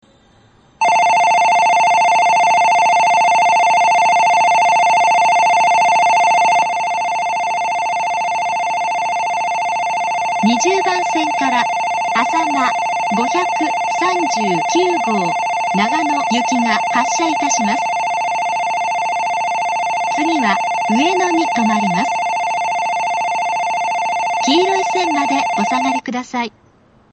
発車時には全ホームROMベルが流れます。
遅くとも東北新幹線全線開業時には、発車ベルに低音ノイズが被るようになっています。ただし、新幹線の音がうるさいので密着収録していてもほとんどわかりません。
２０番線発車ベル あさま５３９号長野行きの放送です。